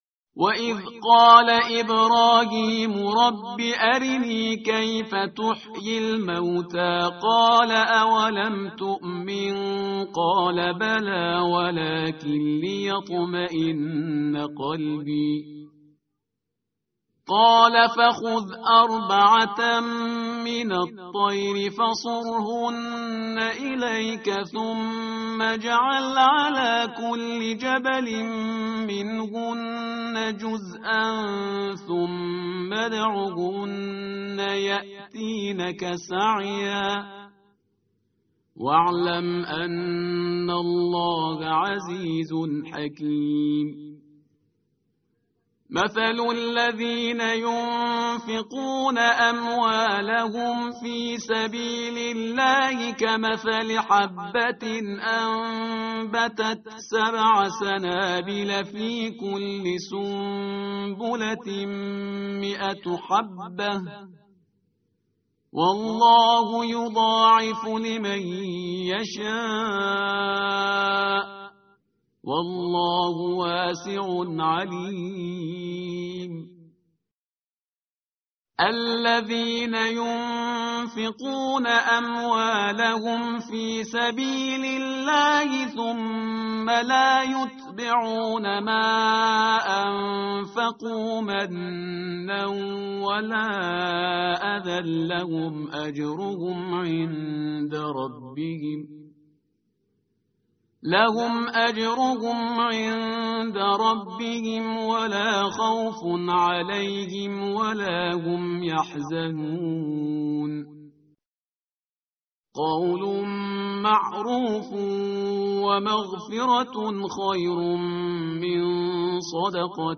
tartil_parhizgar_page_044.mp3